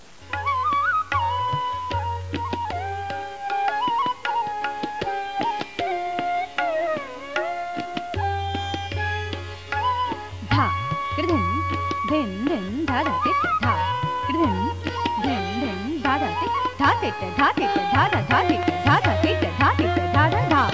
In the second listen for the shahnai (oboe like instrument).